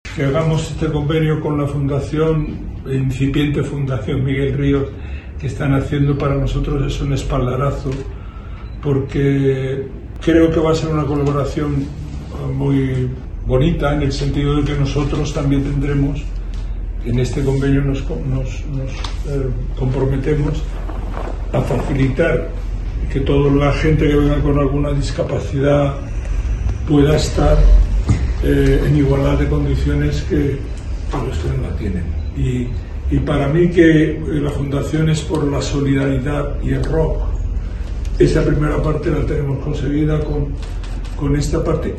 tal y como explicó el propio músico formato MP3 audio(0,80 MB).